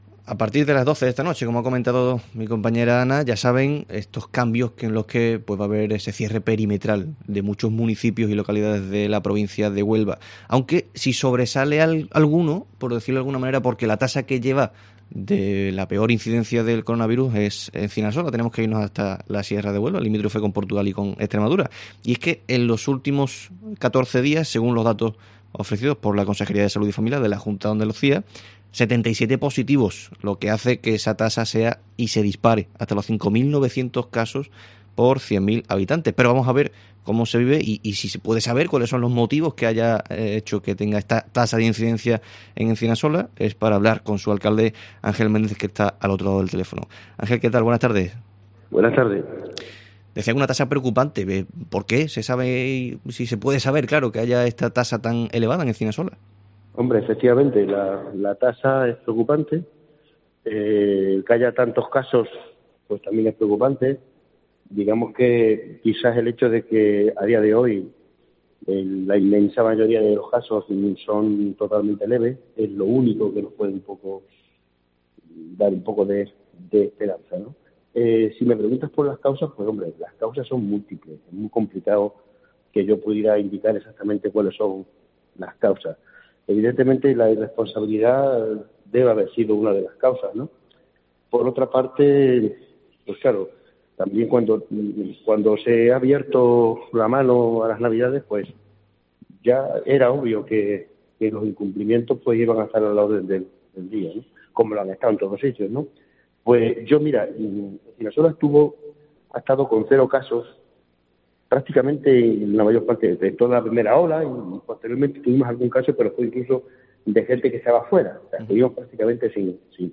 Encinasola es el municipio de Huelva con mayor tasa de incidencia por coronavirus con 5.900 por cada 100.000 habitantes, algo que hemos abordado con su alcalde, Ángel Méndez.